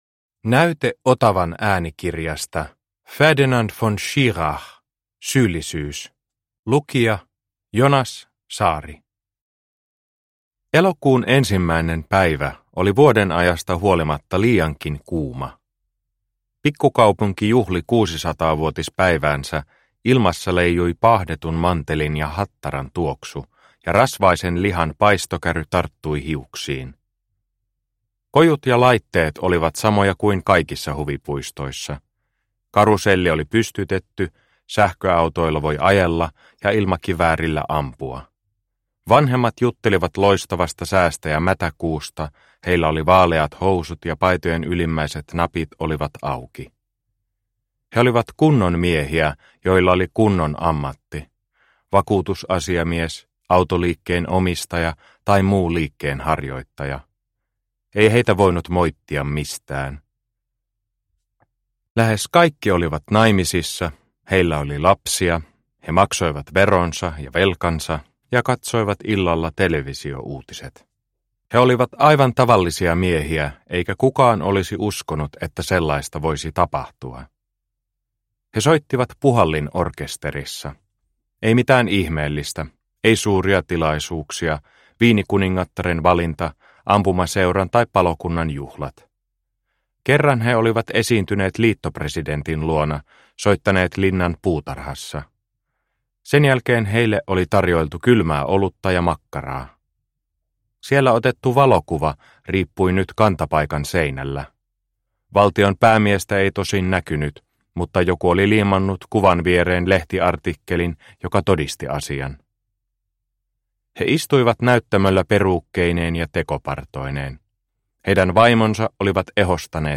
Syyllisyys – Ljudbok – Laddas ner